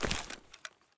46265b6fcc Divergent / mods / Soundscape Overhaul / gamedata / sounds / material / actor / step / earth4.ogg 36 KiB (Stored with Git LFS) Raw History
earth4.ogg